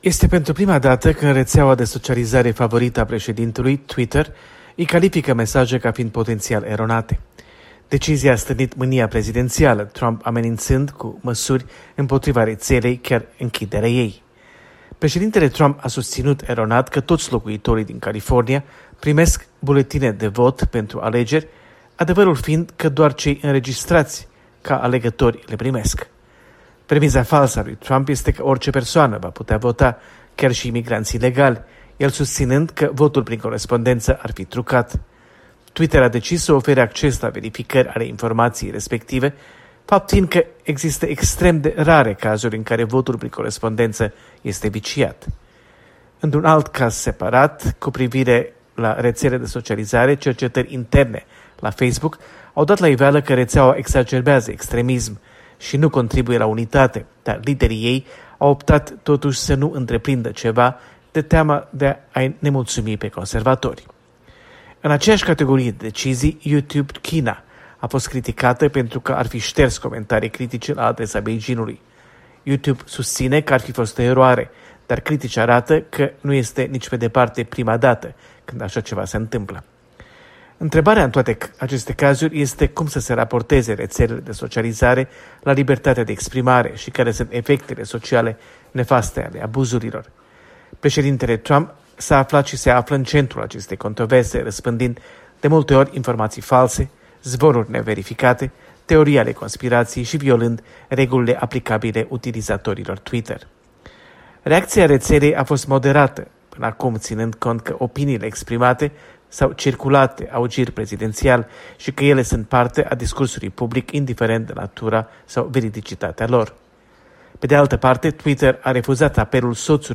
Corespondență de la Washington: Twitter vs Trump